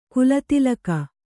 ♪ kulatilaka